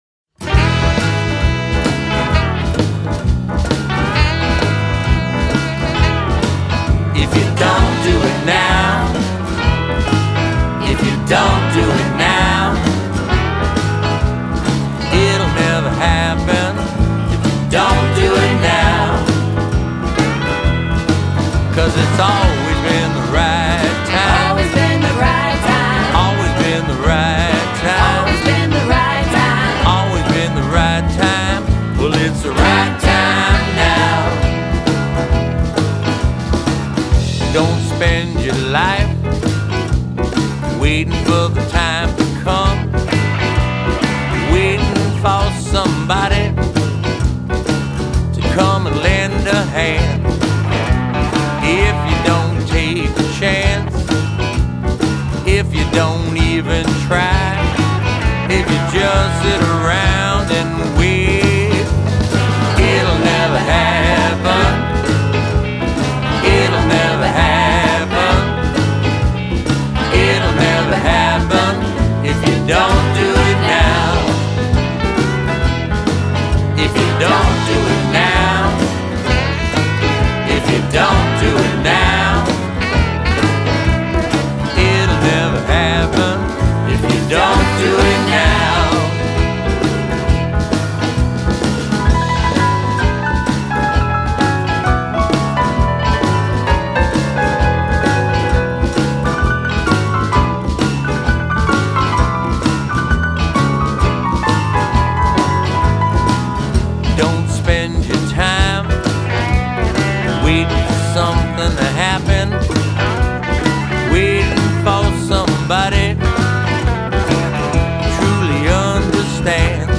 keys
drums, backup vocals
percussion
bass
sax, flute